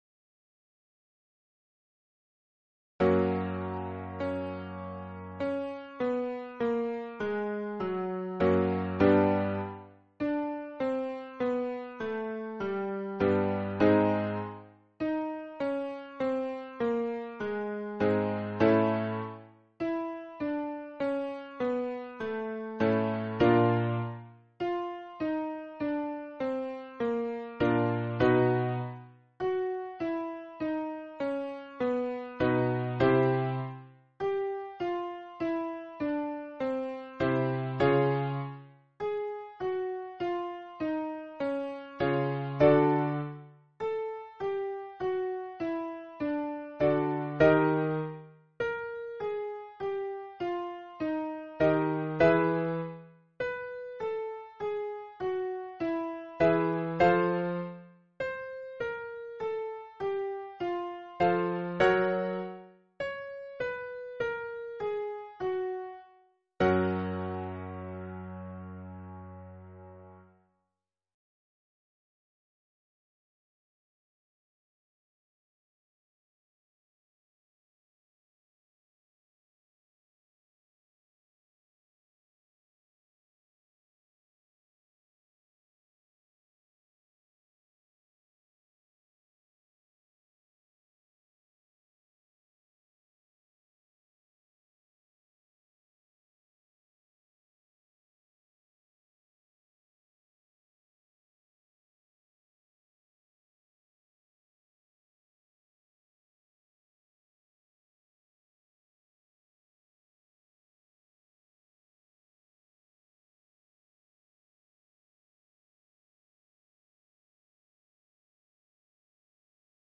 Lesson 4 - Ex 1 - Humming Warm-Up